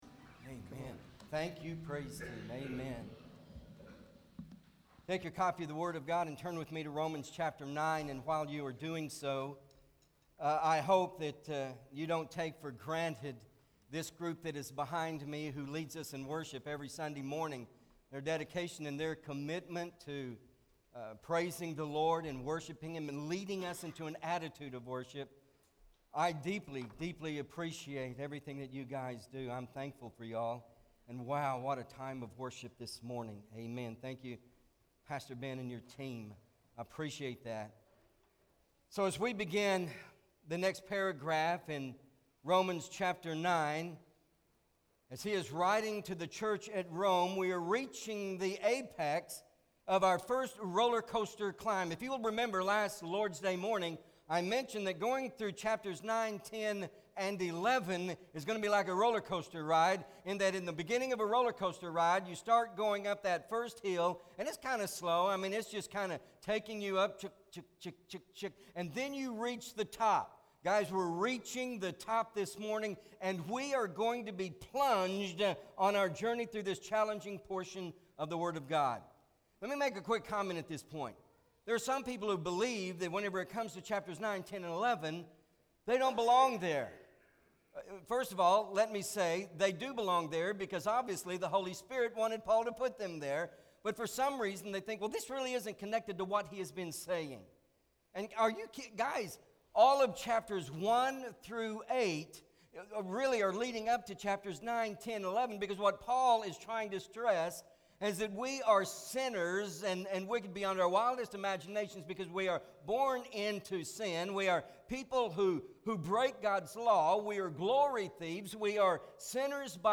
Romans Revealed- The Word of God Does not Fall MP3 SUBSCRIBE on iTunes(Podcast) Notes Sermons in this Series Romans 9: 6-13 Not Ashamed!